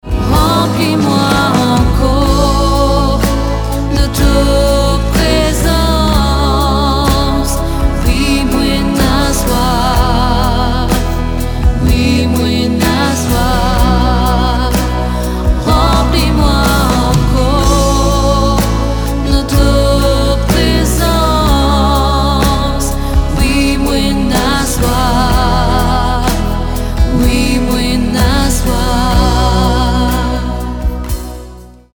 Clavier & Arrangeur
Basse
Guitare
Batterie & Percussions